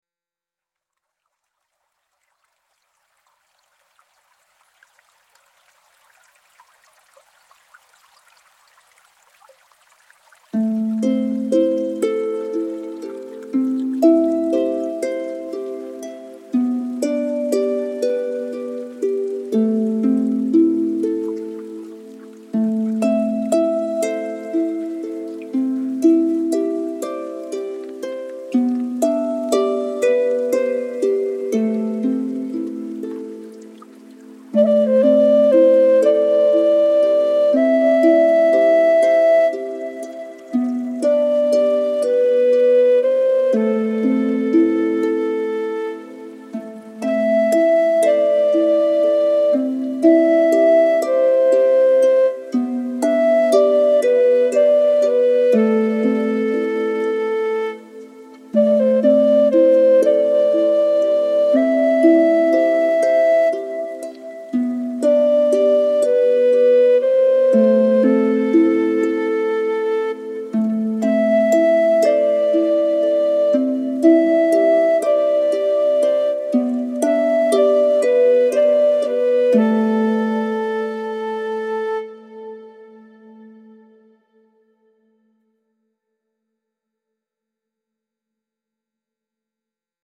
zen garden inspired music with koto, bamboo flute and water sounds